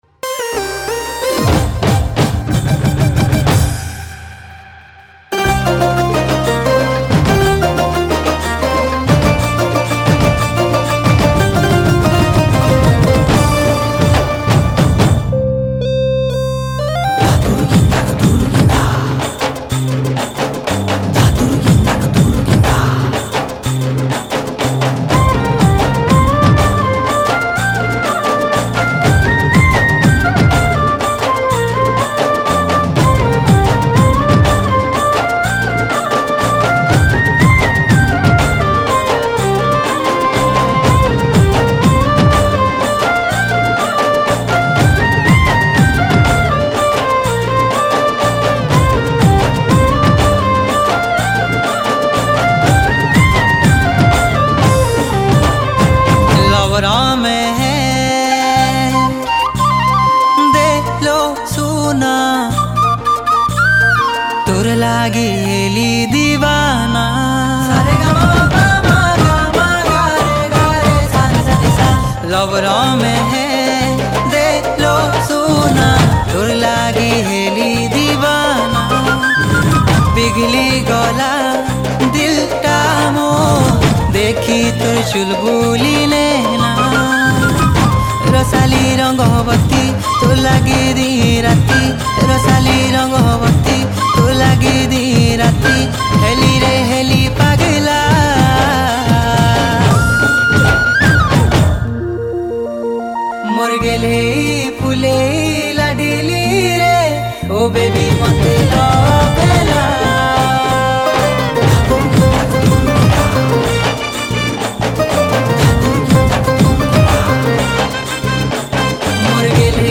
Sambapuri Single Song 2022